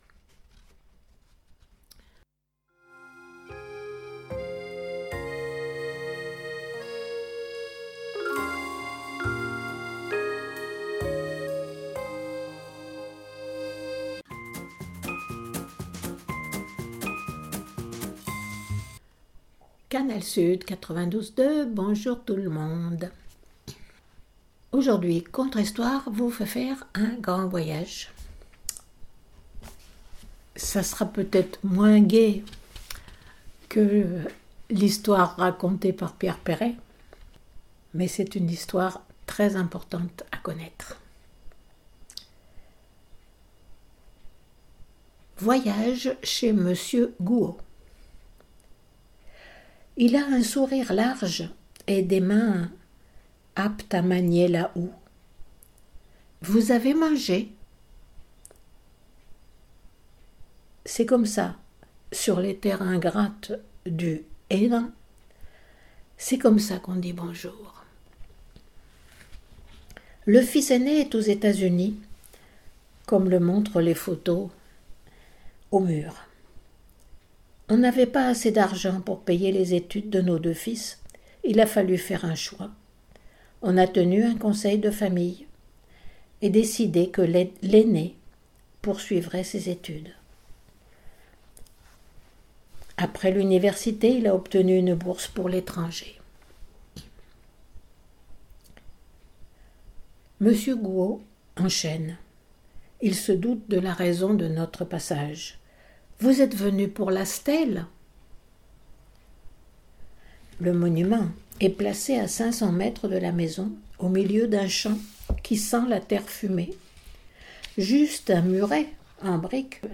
Musique de Buxtehude